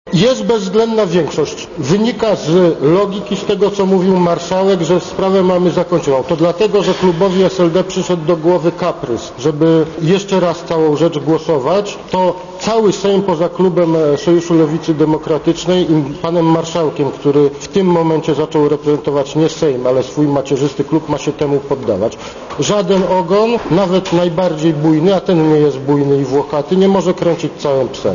Mówi Ludwik Dorn